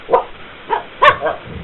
dog_play.mp3